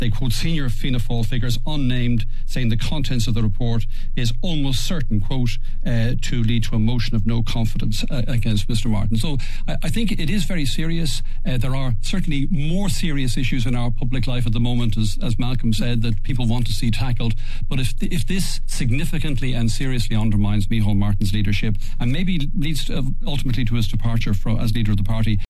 Former RTÉ broadcaster Brian Dobson says the controversy may prove far more than a footnote in Irish political history: